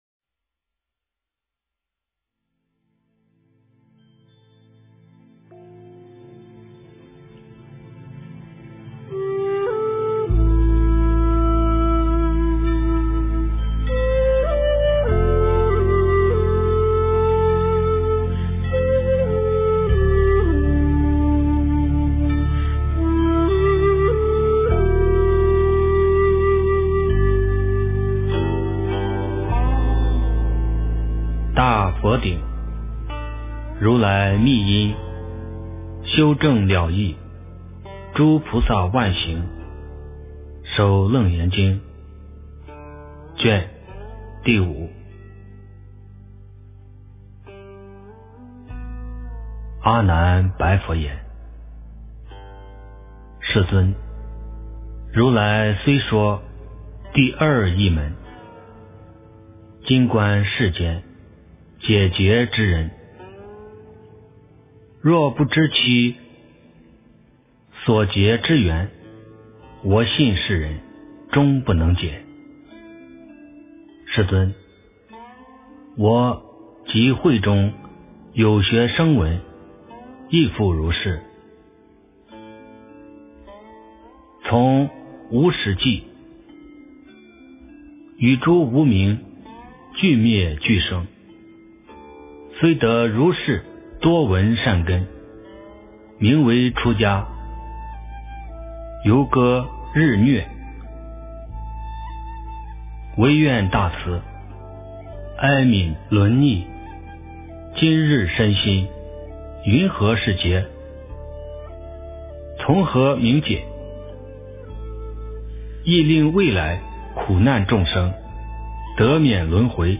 楞严经第05卷-念诵
诵经